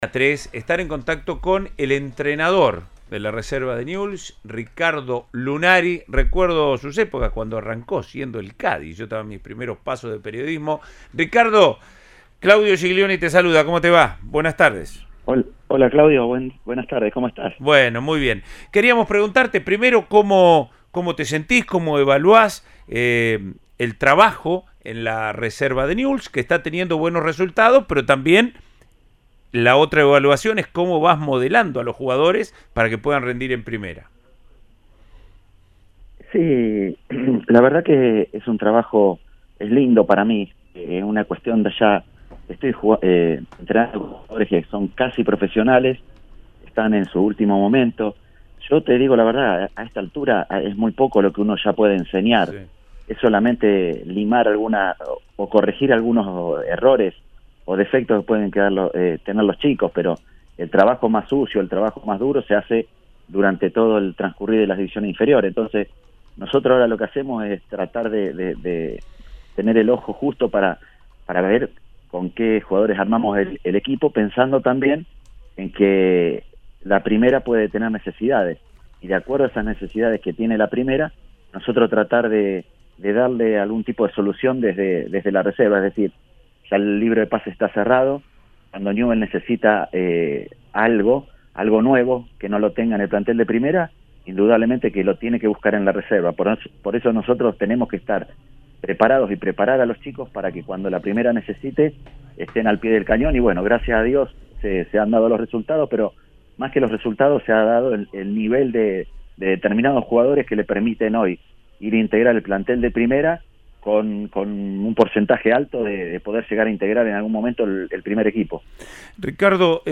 El ex futbolista y entrenador de la Reserva habló en Cadena 3 Rosario de distintos temas de la actualidad de Newell’s y no esquivó la racha negativa que acumula el equipo en los partidos con Central.